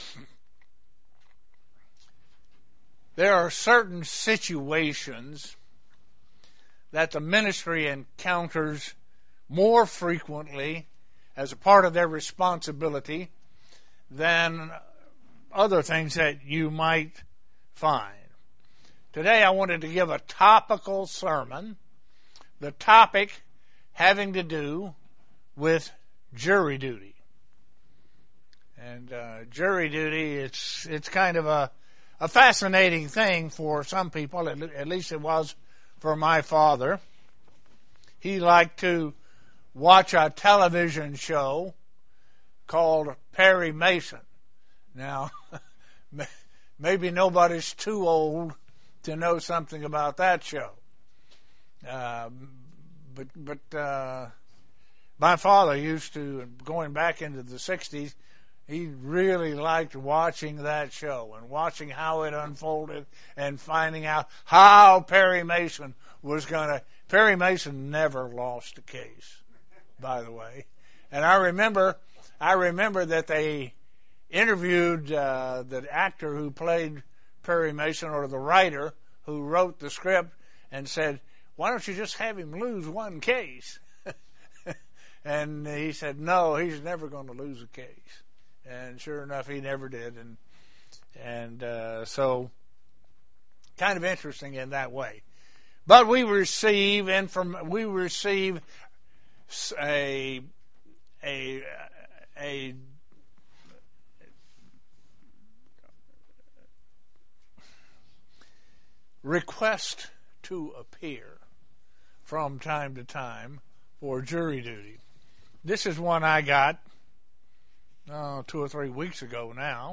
Sermons
Given in Elmira, NY